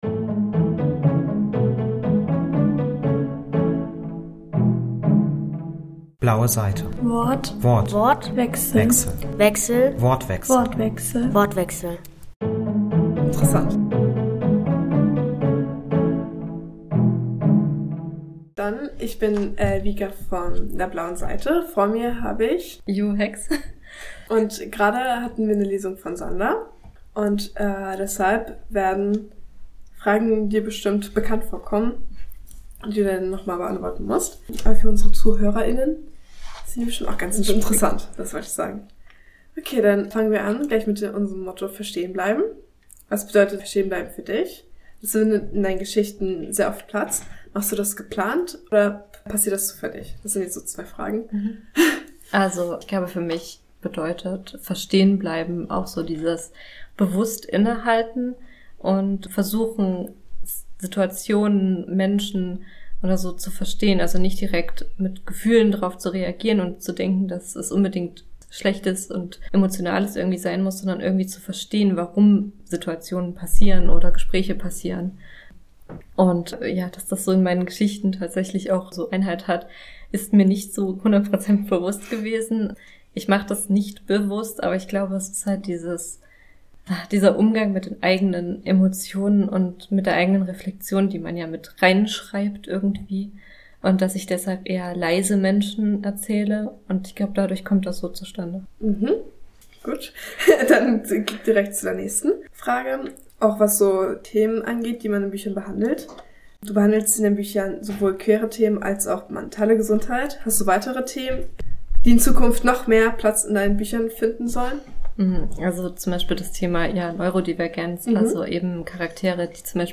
im Rahmen der 19. Lübecker Jugendbuchtagen. Inwiefern psychische Probleme und der Umgang damit sowohl im echten Leben als auch im Buch eine Rolle spielen, erfahrt ihr in dieser Folge.